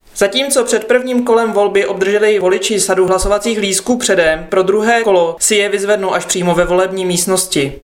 Vyjádření Evy Krumpové, 1. místopředsedkyně ČSÚ, soubor ve formátu MP3, 700.04 kB